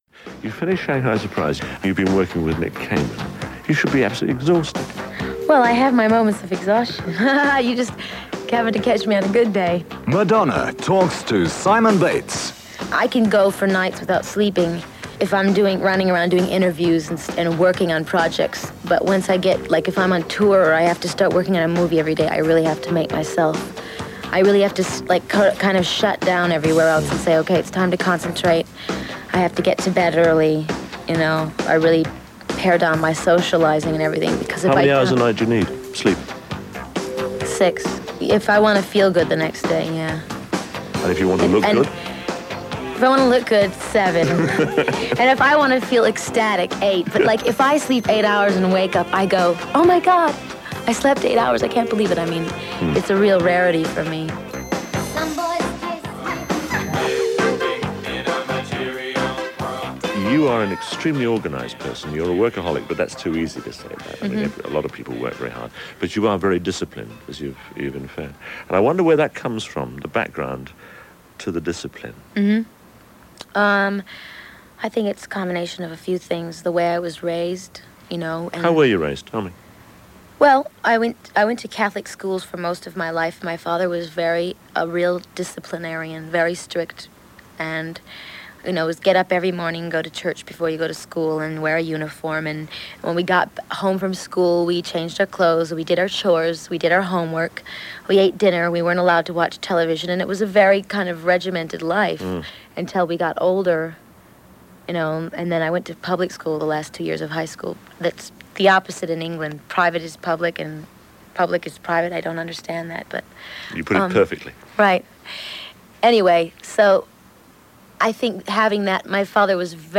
On December 22 1986, an exclusive interview with Madonna conducted by Simon Bates was broadcast on BBC Radio 1 in the U.K.